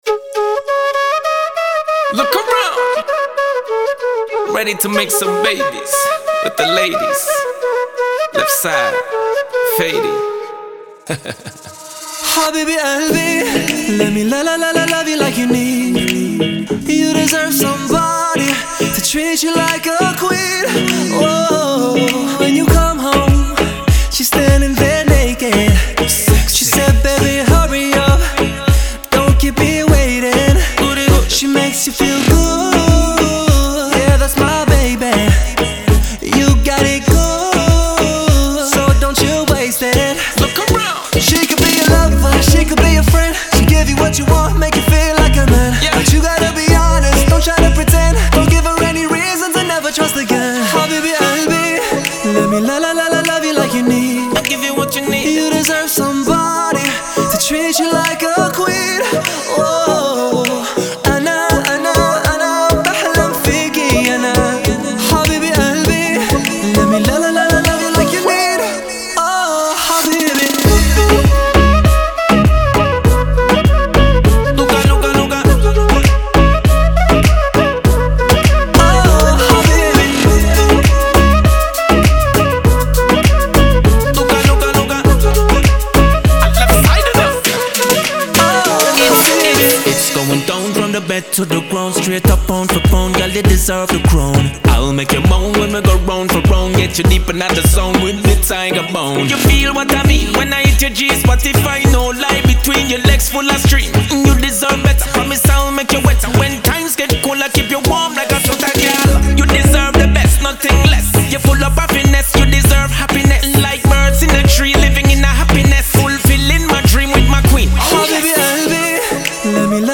это зажигательная песня в жанре регги и поп